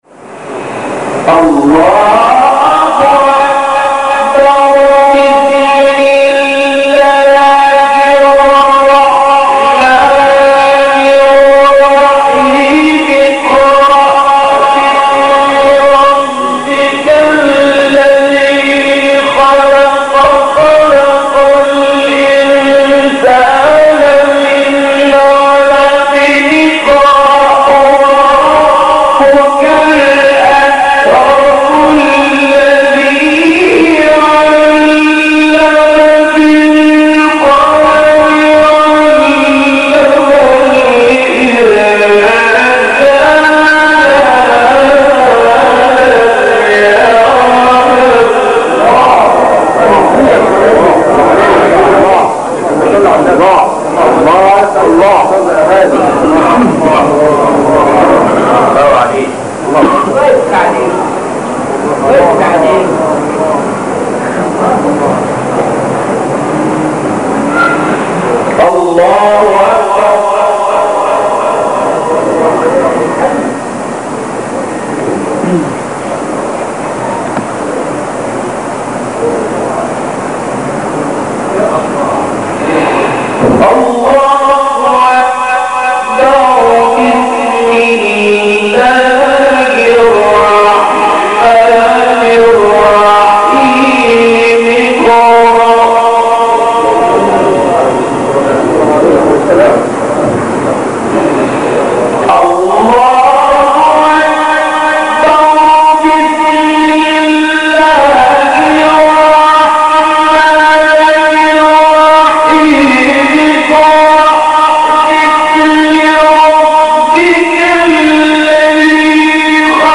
مقام : رست